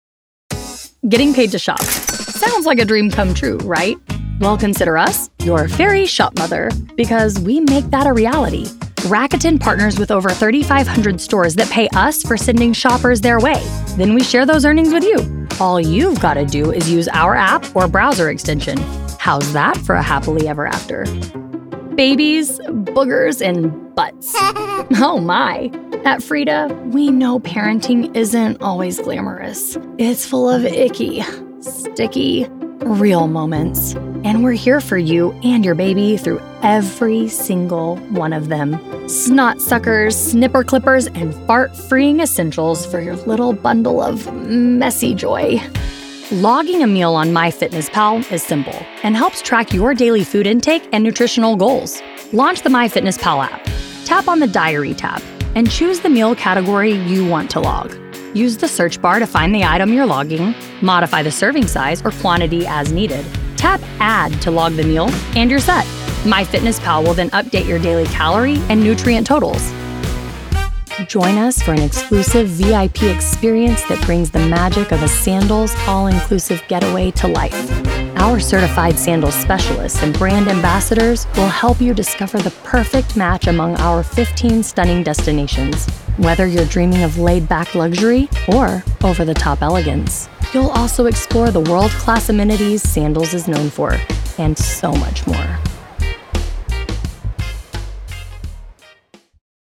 Raspy, real person, millennial with engaging, fun personality.
Explainer
Neutral, Neutral English, General American English, Southern
My sound is warm, raspy, approachable, conversational, confident, and sincere—capable of humor, nuance, and emotional depth.